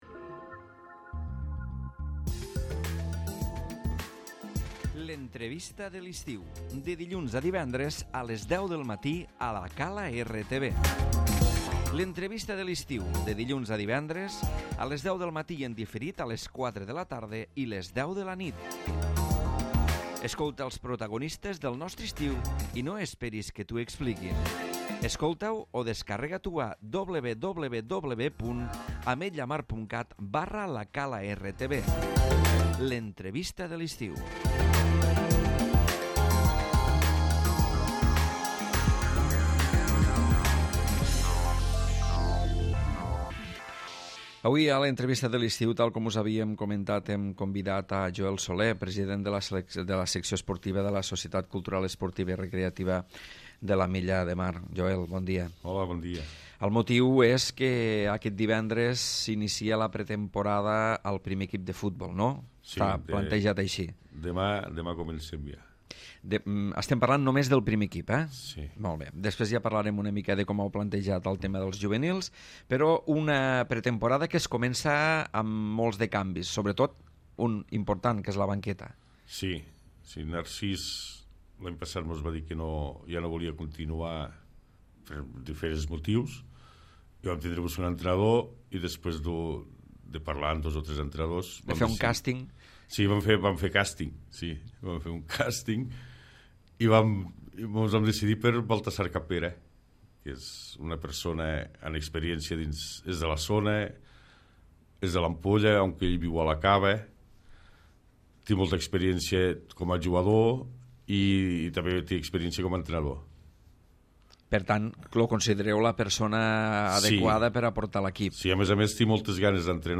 L'Entrevista de l'Estiu